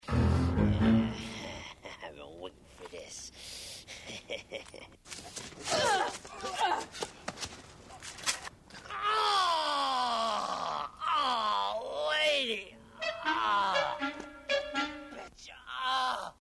We wind up with a largely silent fight sequence perked up by the manly grunting of the participants.
Later, there's another fight scene with Tori and a couple of bald thugs, and the editor still hasn't figured out that if you don't put sound effects in a fight scene, it just looks boring and unfinished.
slashdance-thugfight.mp3